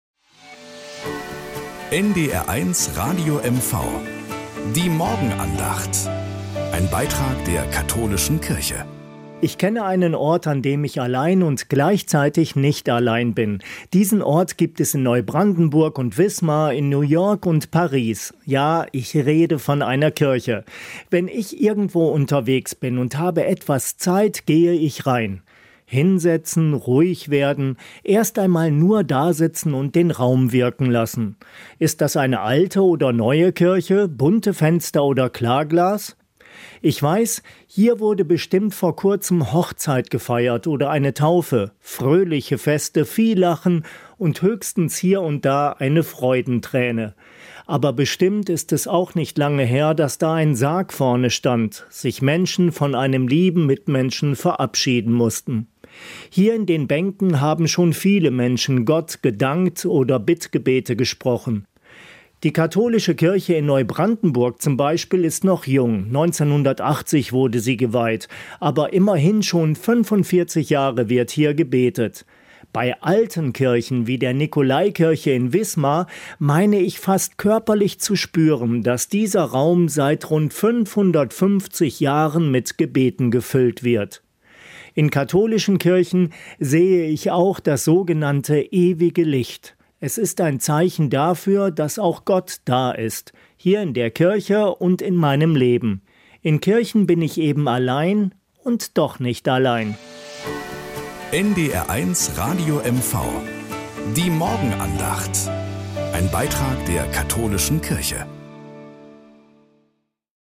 Nachrichten aus Mecklenburg-Vorpommern - 10.07.2025